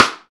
Crunchy Snare Drum Sample F Key 37.wav
Royality free snare sound tuned to the F note. Loudest frequency: 2044Hz
crunchy-snare-drum-sample-f-key-37-exT.mp3